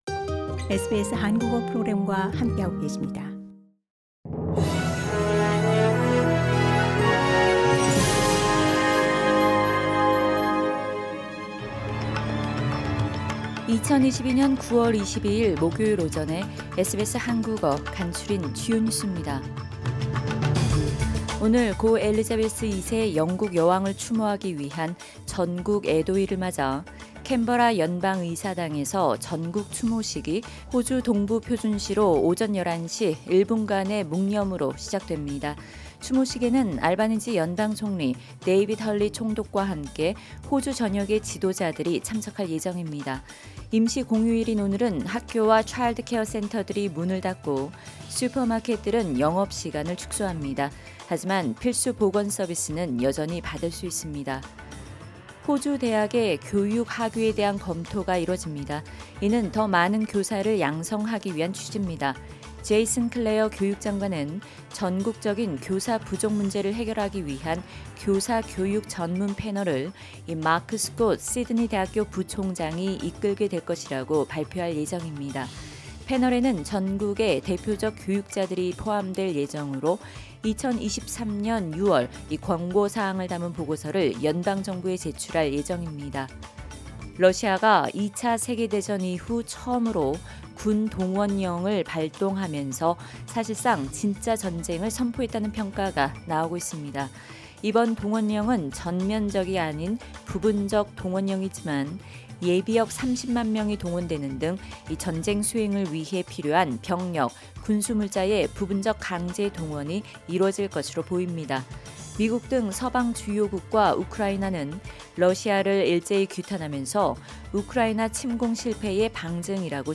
2022년 9월 22일 목요일 아침 SBS 한국어 간추린 주요 뉴스입니다.